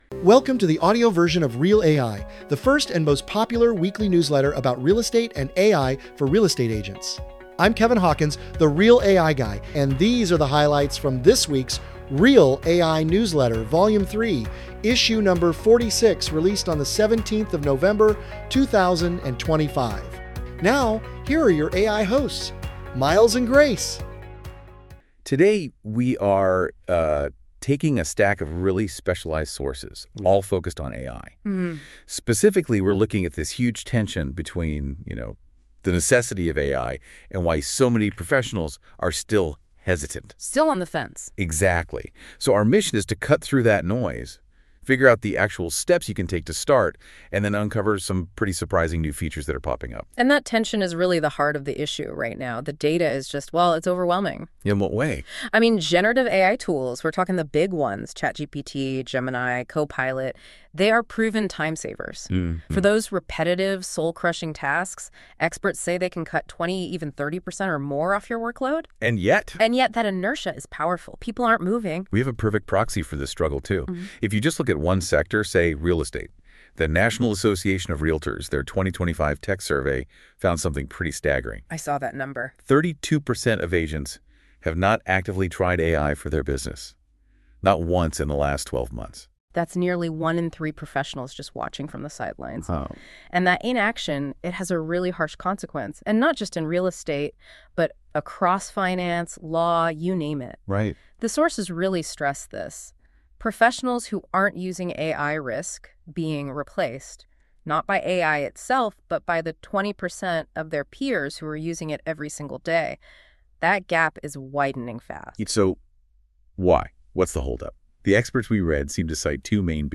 AI generated